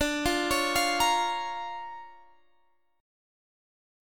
Listen to DmM7#5 strummed